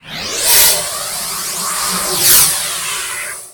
lock2.ogg